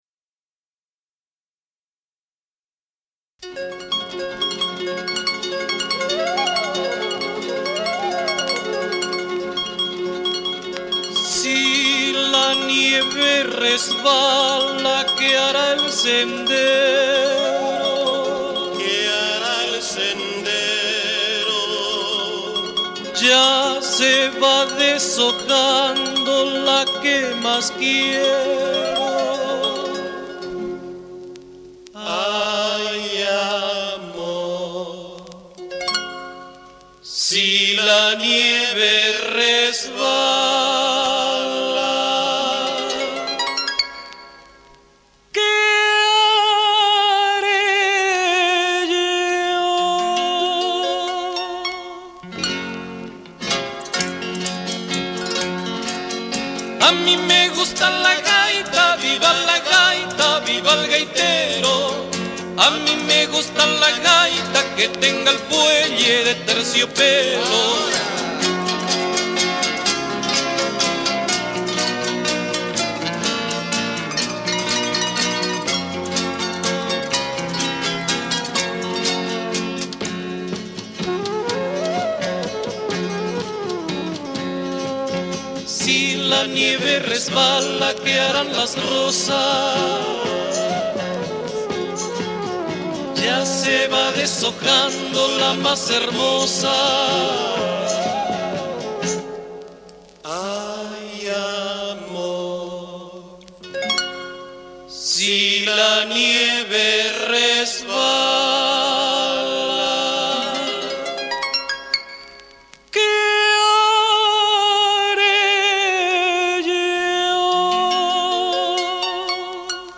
Recording Studio Madeleine, Brussels